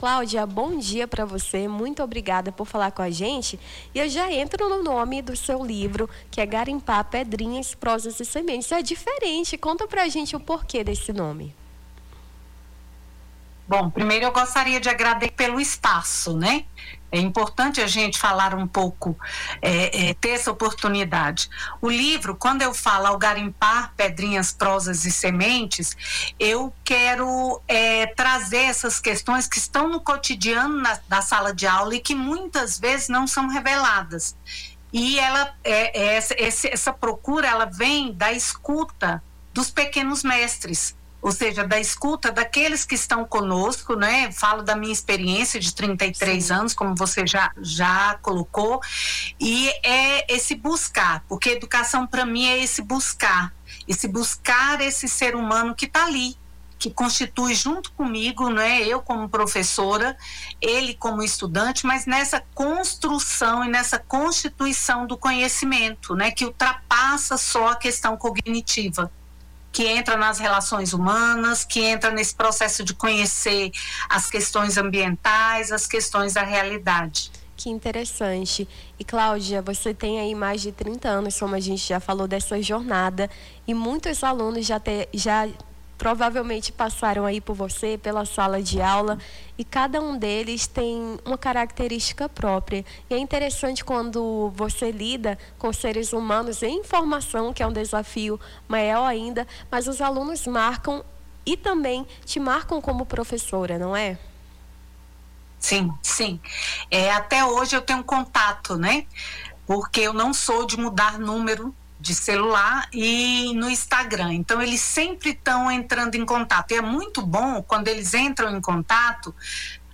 Baixar Esta Trilha Nome do Artista - CENSURA - ENTREVISTA (EDUCA__O CAMINHO CONHECIMENTO) 24-10-25.mp3 Foto: internet/ Freepik Facebook Twitter LinkedIn Whatsapp Whatsapp Tópicos Rio Branco Acre Livro Educação